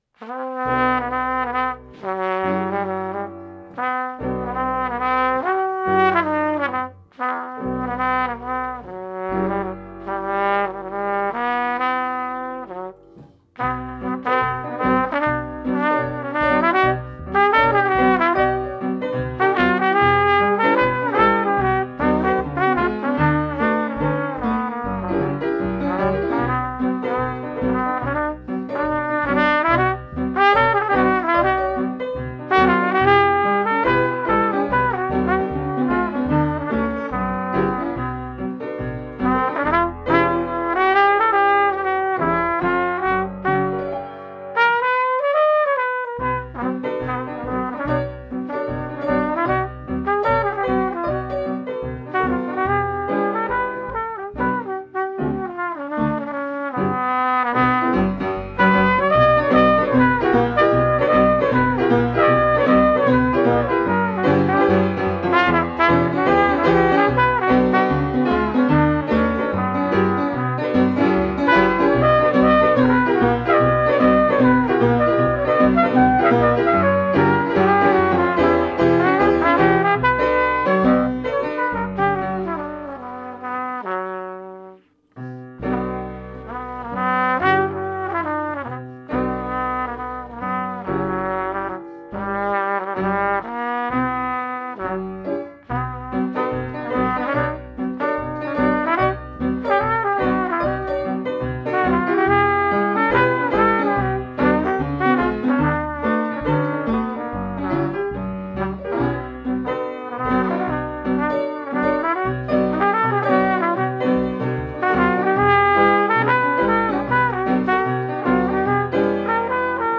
Trumpet, piano and electric upright bass.